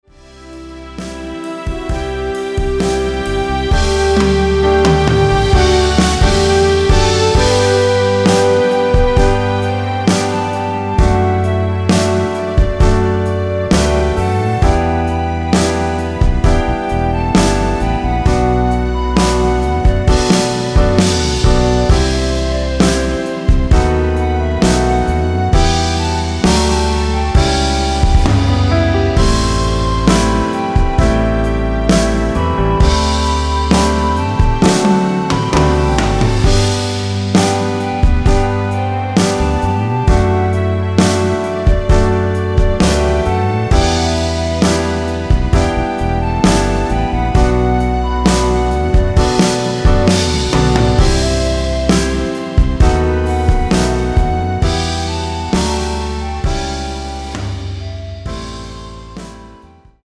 원키에서(+1)올린 멜로디 포함된 MR입니다.(미리듣기 확인)
앞부분30초, 뒷부분30초씩 편집해서 올려 드리고 있습니다.